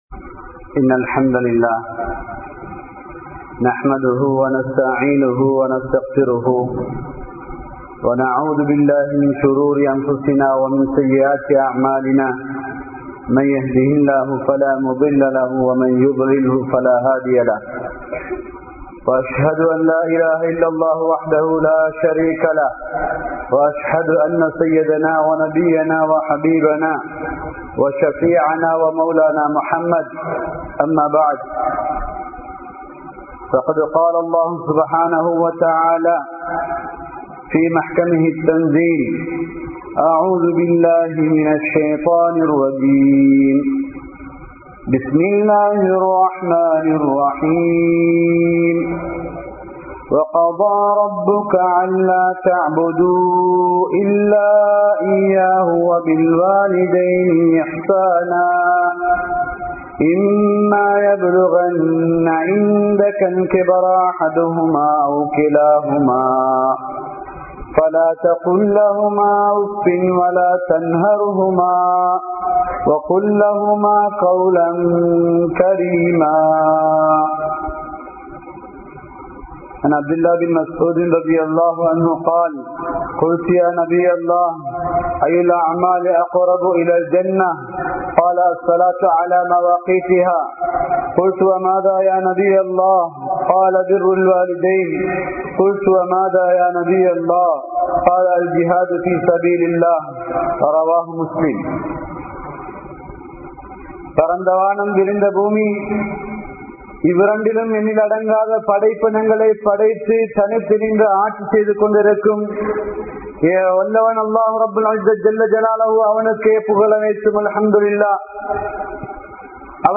Petroarhalai Mathiungal (பெற்றோர்களை மதியுங்கள்) | Audio Bayans | All Ceylon Muslim Youth Community | Addalaichenai
Masjidhus Salam Jumua Masjidh